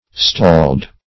Stalled - definition of Stalled - synonyms, pronunciation, spelling from Free Dictionary
Stalled \Stalled\ (st[add]ld or st[add]l"[e^]d), a.